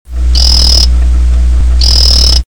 Dart Frog Calls